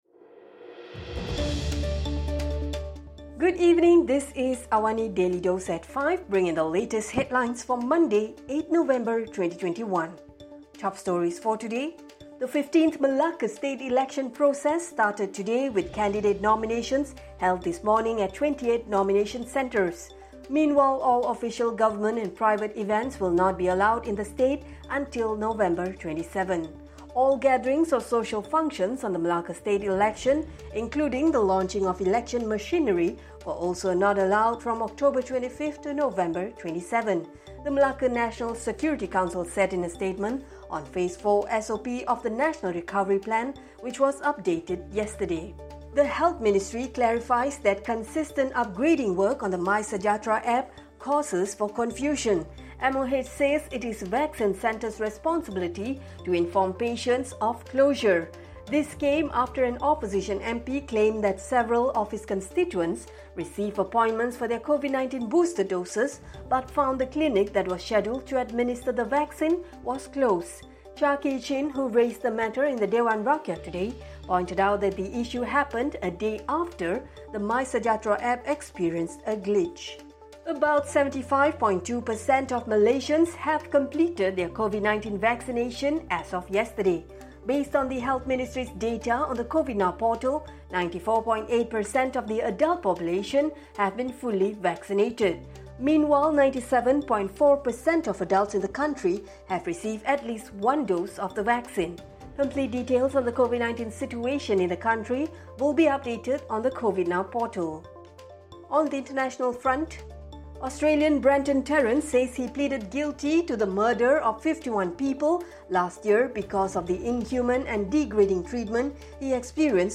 Listen to the top stories of the day, reporting from Astro AWANI newsroom — all in 3-minutes.